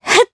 Laudia-Vox_Jump_jp.wav